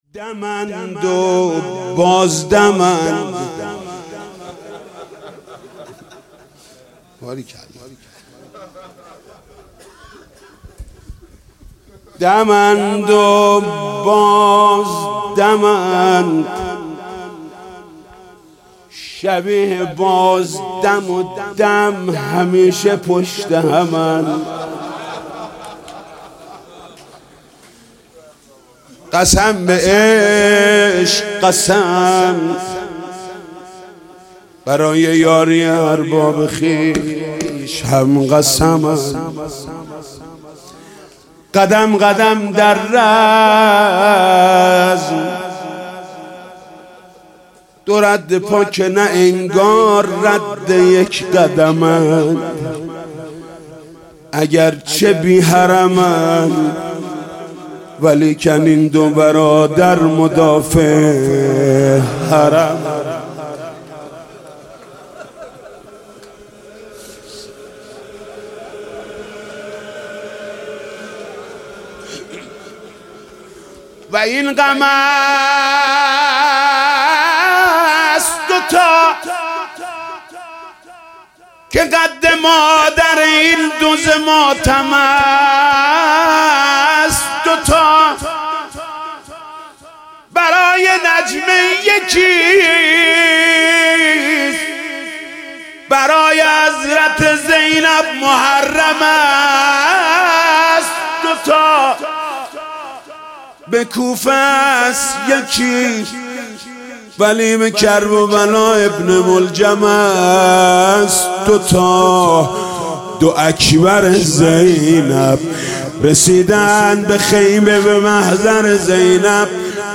دانلود مداحی و روضه خوانی شب چهارم ماه محرم در سال 1396
مداحی شب چهارم محرم 96 با نوای مداحان اهل بیت (ع)